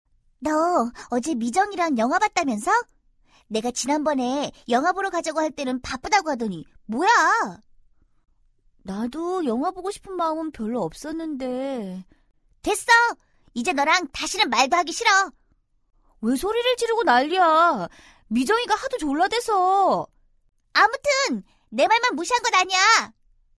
109쪽-내레이션.mp3